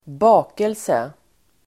Uttal: [²b'a:kelse]